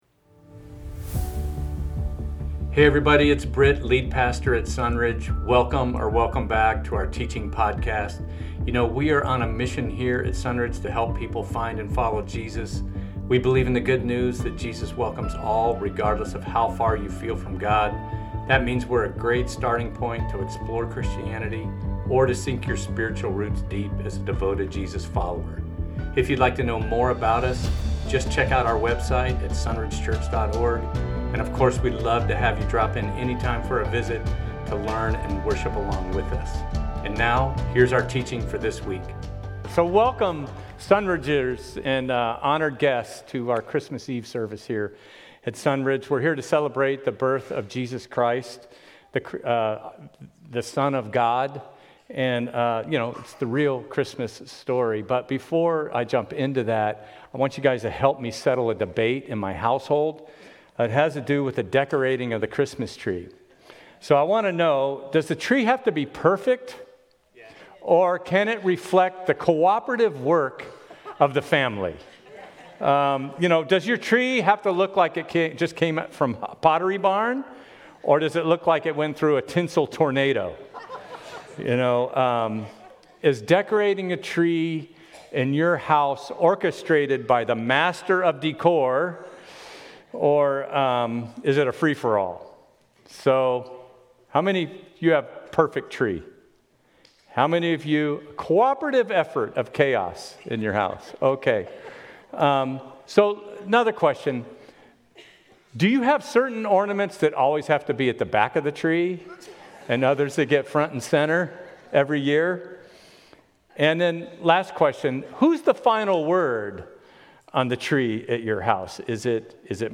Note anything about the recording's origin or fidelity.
Christmas Eve 2024 - Sermons at Sunridge Church in Temecula.